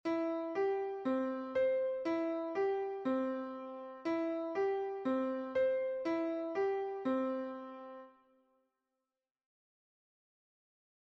Pero antes, a ver que tal vas de oído, relacionando os seguintes audios coas partituras que se che ofrecen, un ditado melódico cun propósito real.